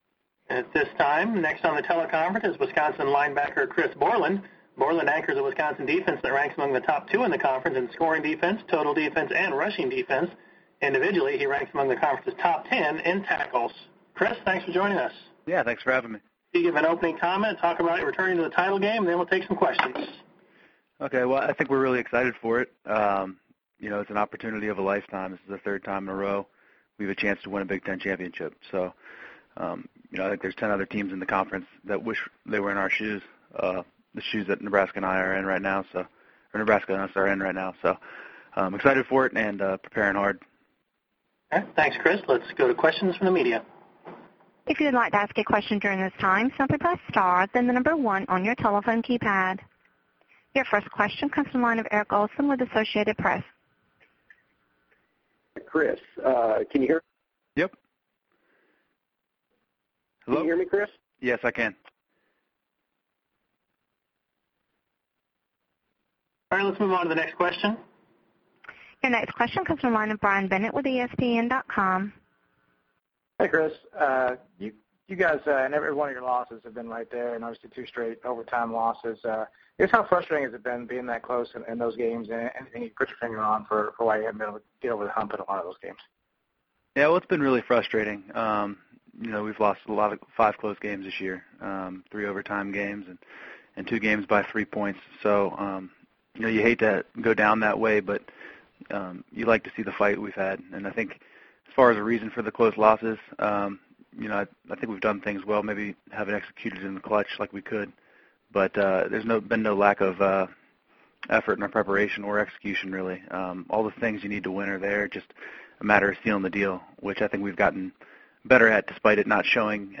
Listen to Bret Bielema's teleconference: